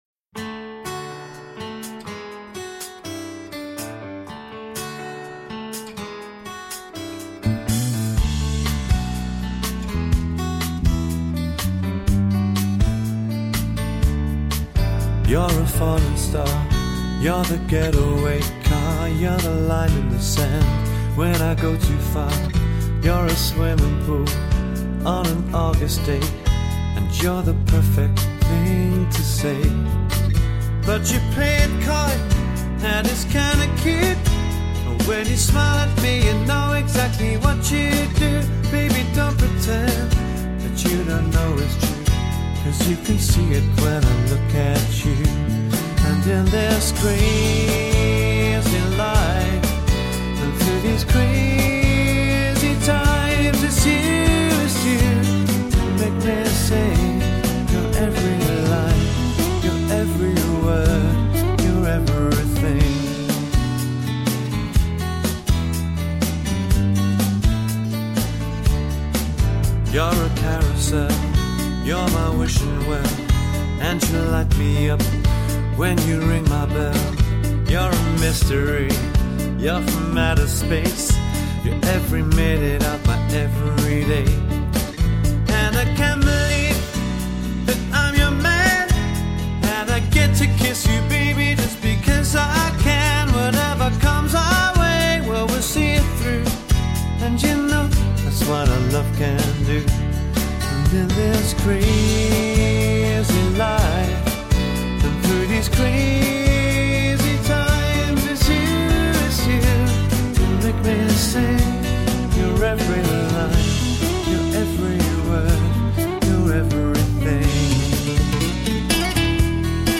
Swing & Jazz Singer